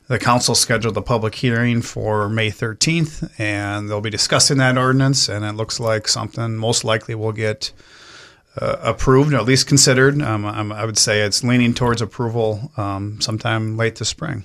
Jaunich says a public hearing and 1st Reading is set for next month: